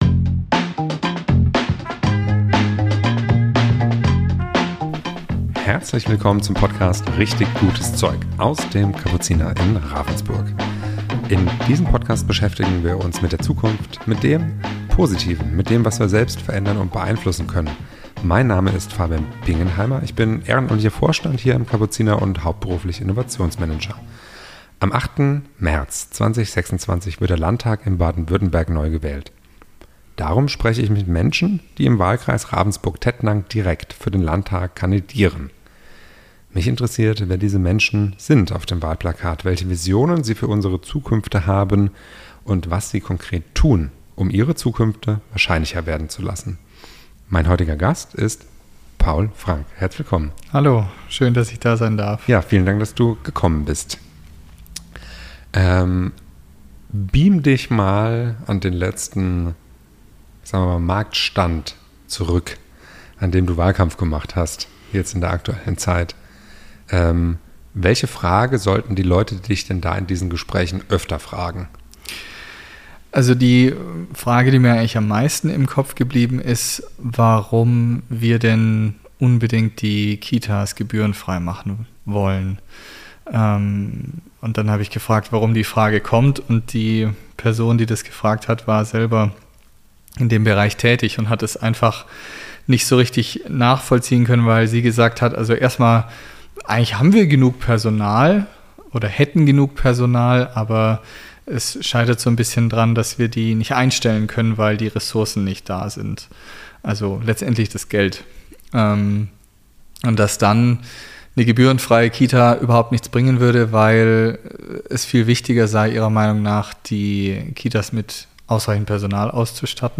Diese Folge wurde in Ravensburg am 11. Februar 2026 aufgenommen.